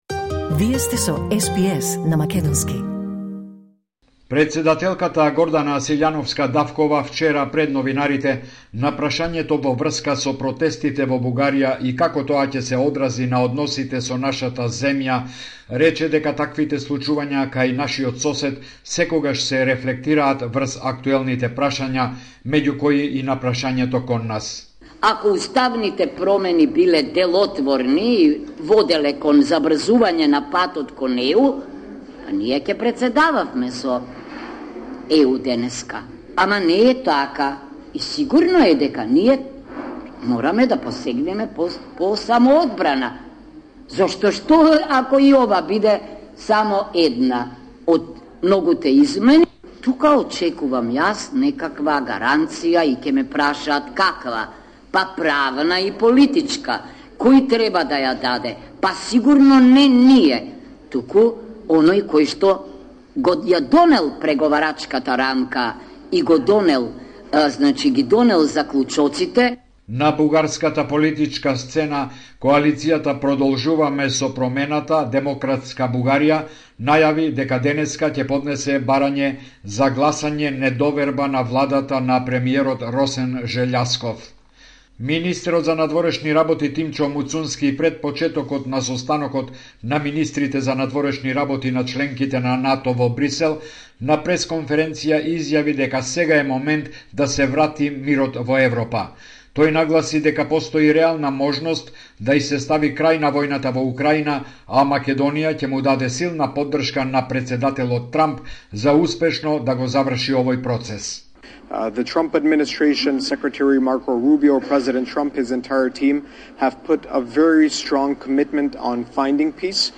Извештај од Македонија 4 декември 2025